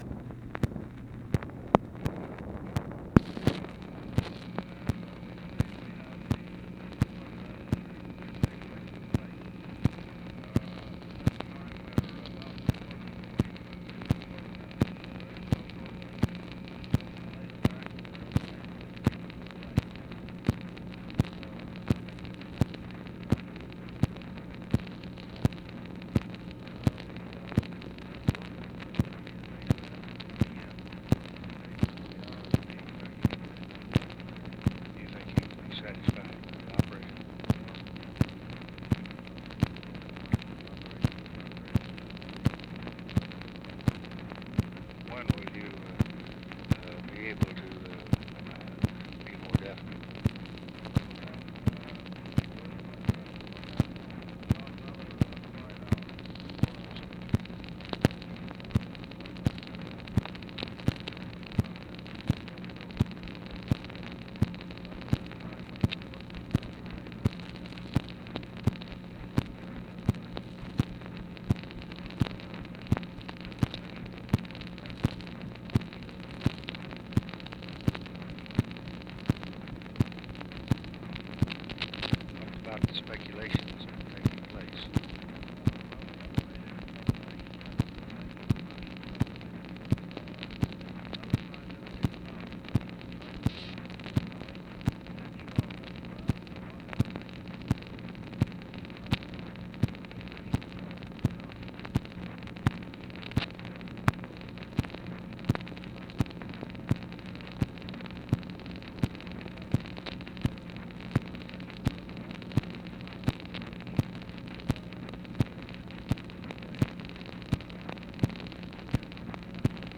INAUDIBLE DISCUSSION OF PROGRESS OF MARINER IV MARS SPACE MISSION; LBJ'S VISIT THIS MORNING WITH JAMES WEBB
Conversation with WILLIAM PICKERING, July 15, 1965